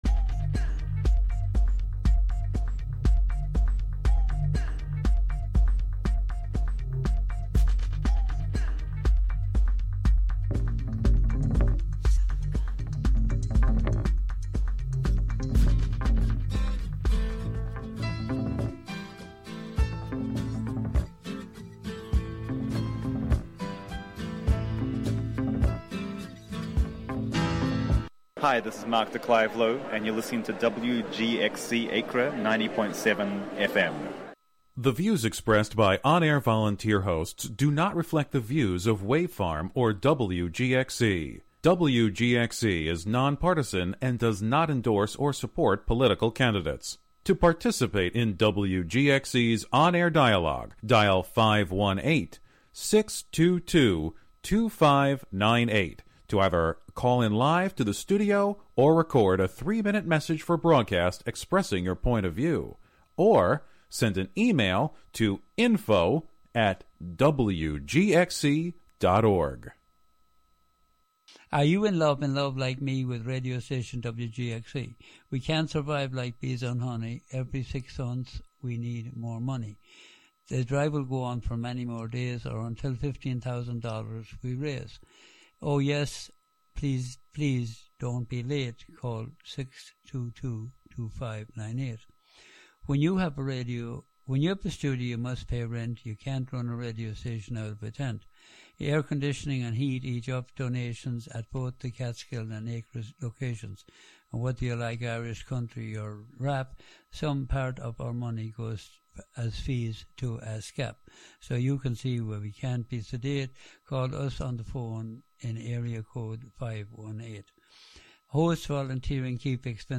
On this monthly program, “La Ville Inhumaine” (The Inhuman City), you will hear music, found sounds, words, intentional noise, and field recordings all together, all at once.